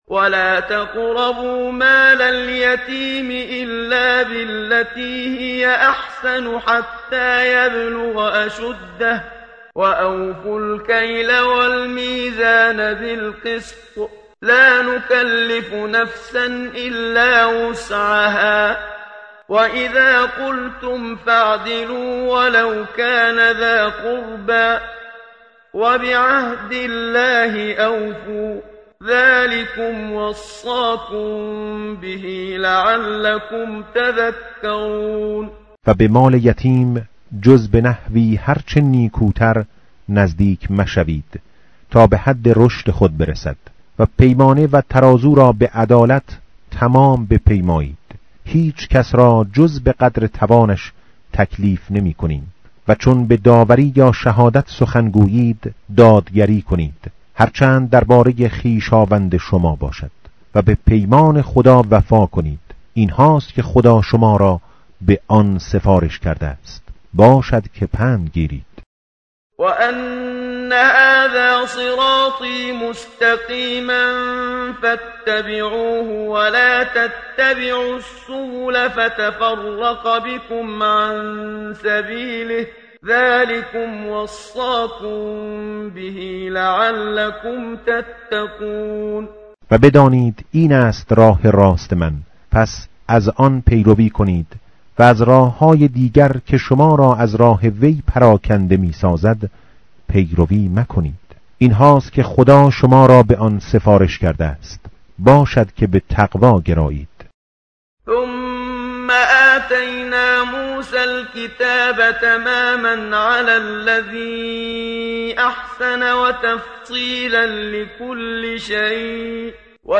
متن قرآن همراه باتلاوت قرآن و ترجمه
tartil_menshavi va tarjome_Page_149.mp3